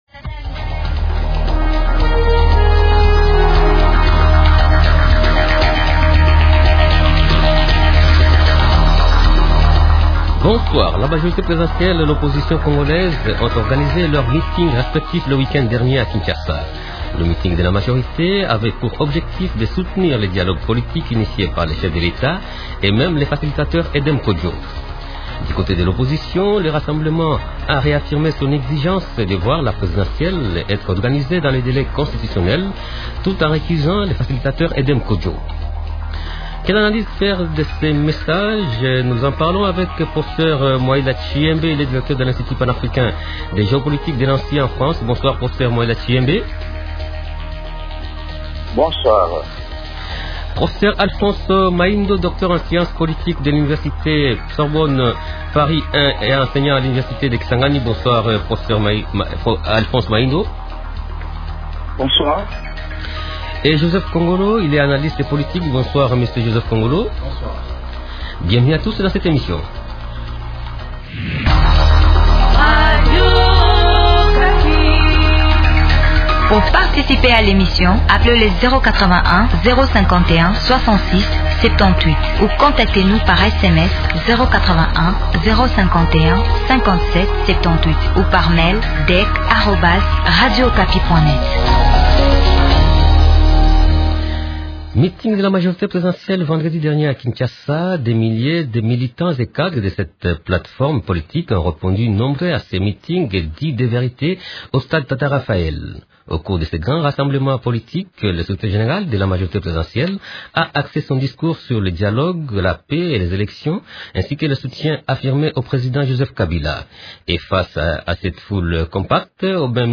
Participent au débat de ce soir :